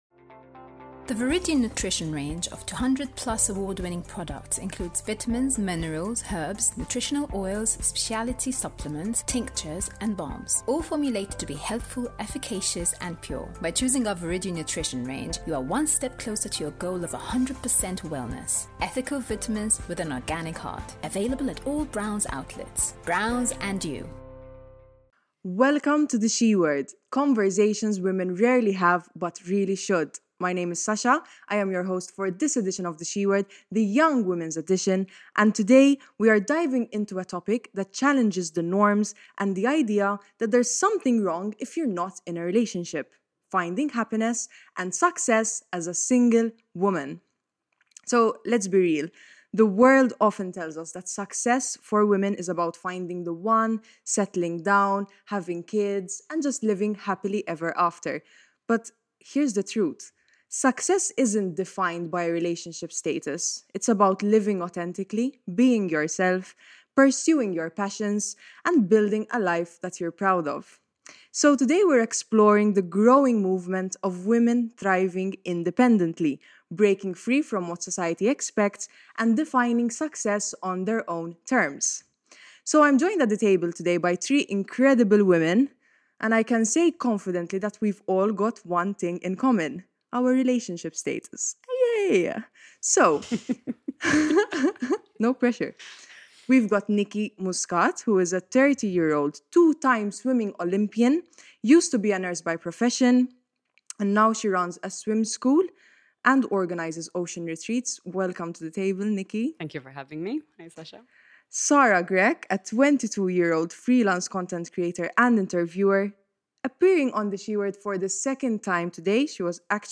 Between the laughs and shared experiences, the discussion takes a powerful turn, encouraging women to know their worth, refuse to settle, and to demand the love and respect they deserve.